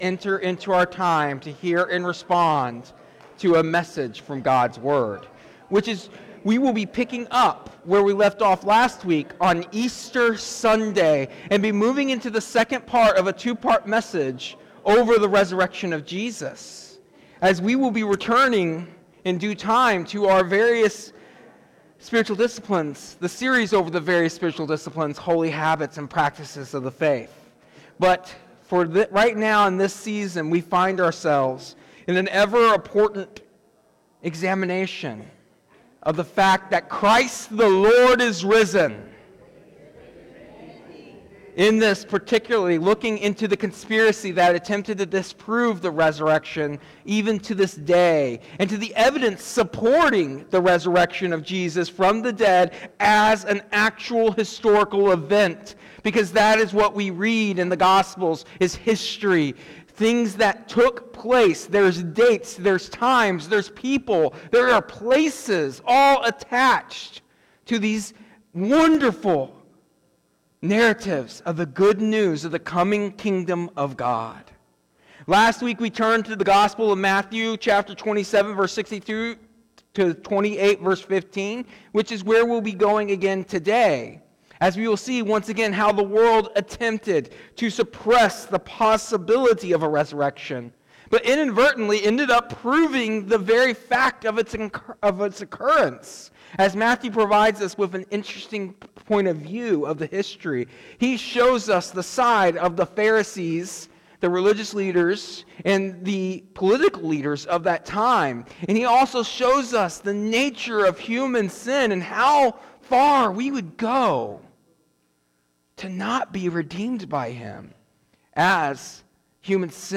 In today’s sermon, we will reiterate the significance of Jesus’ resurrection, emphasizing that despite various attempts to disprove it, the resurrection remains a historical fact. It will highlight further the failed efforts of the religious leaders and Roman guards to secure Jesus’ tomb, which ironically serve to validate the miracle of the resurrection. The pastor also examines additional proofs, such as the role of women as the first witnesses, demonstrating that their testimony, often dismissed in their time, lends credibility to the event.